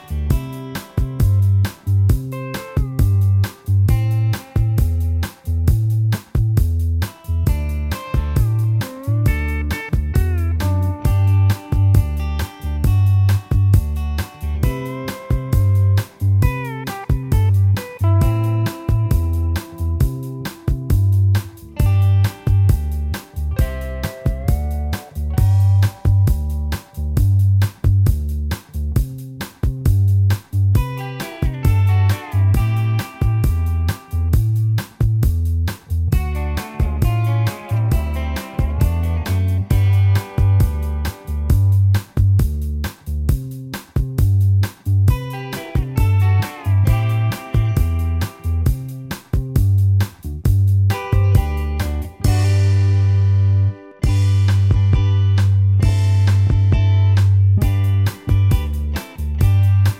Minus Guitars Soft Rock 6:15 Buy £1.50